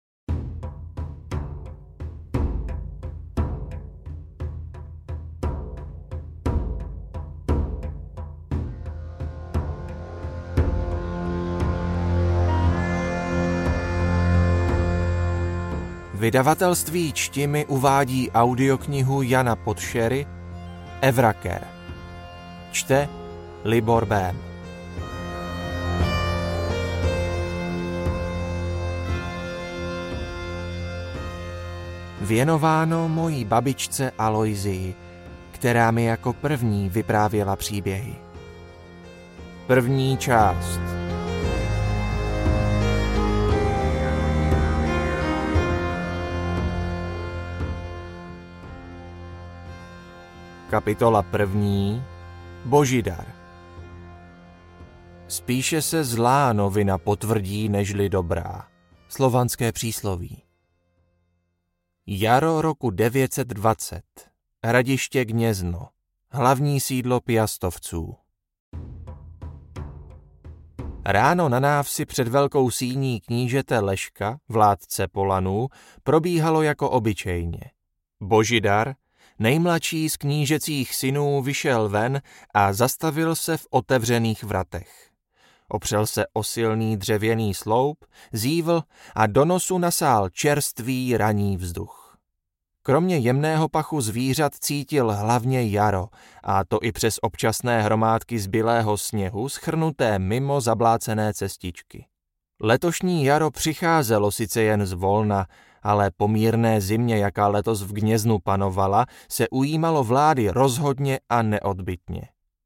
Hudba: Alexander Nakarada